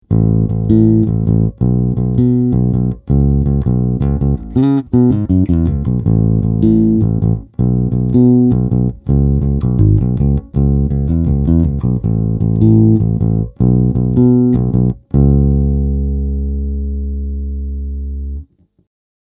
Seuraavat pätkät on äänitetty Squier Jazz -bassolla (vm. 1985) suoraan XLR-lähdön kautta Apple Garagebandiin:
Zoom B3 – puhdas vahvistin (Mark Bass -malli)